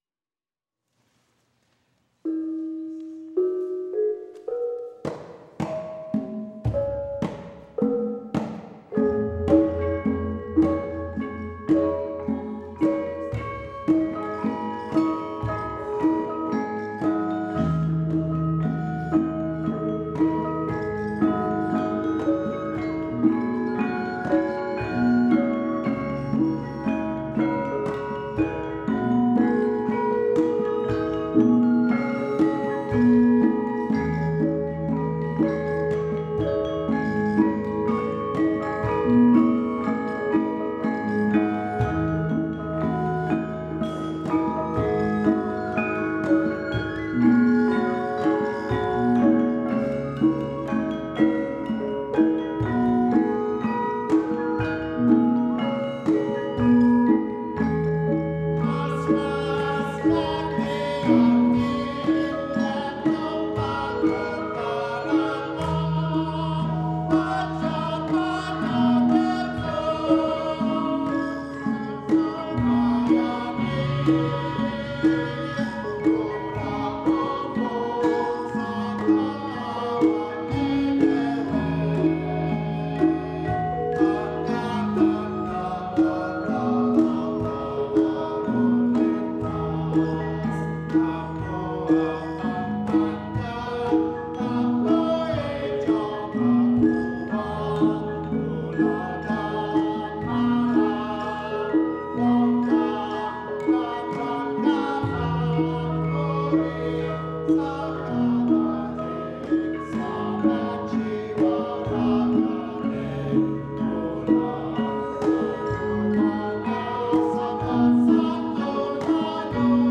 Gamelan Nyai Saraswati
Lancaran Sopan Santun Lalu Lintas, laras slendro pathet sanga, by Sarwanto (1988)
This simple song, written in Javanese language and for Javanese gamelan, outlines correct traffic etiquette.